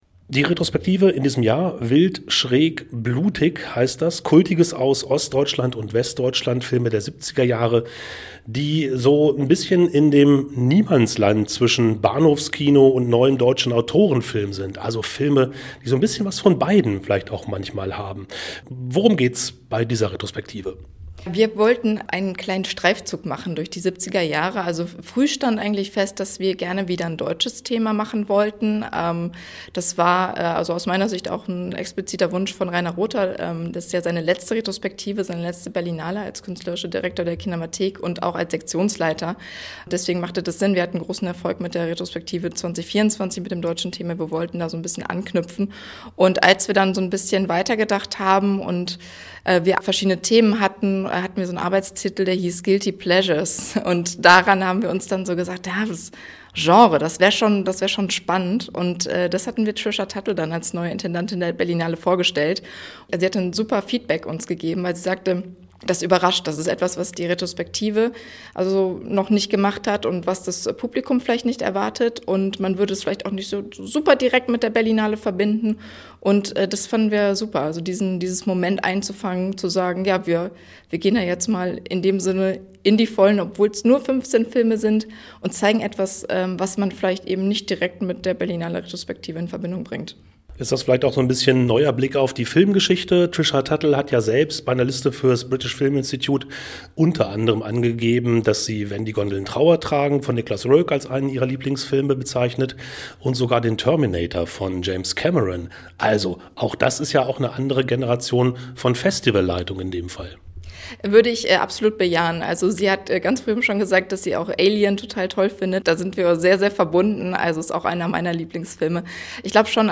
Radiointerview